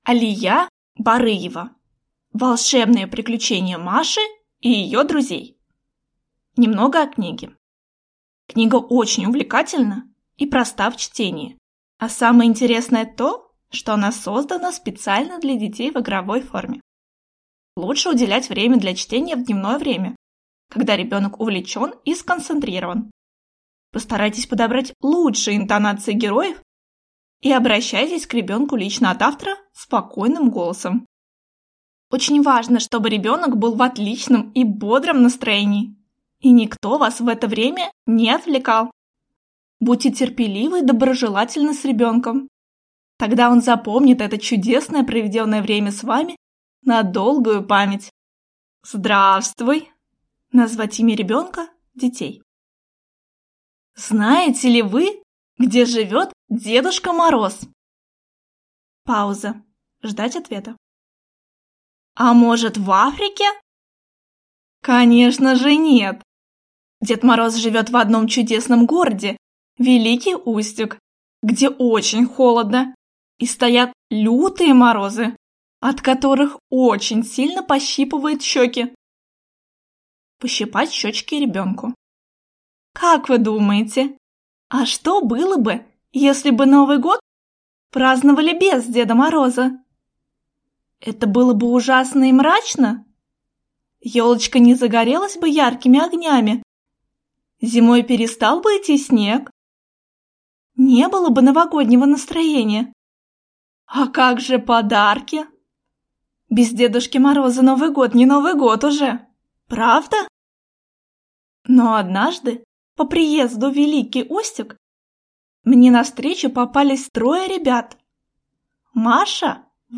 Аудиокнига Волшебные приключения Маши и её друзей | Библиотека аудиокниг